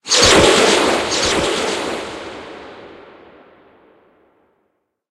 Cri de Lézargus Gigamax dans Pokémon HOME.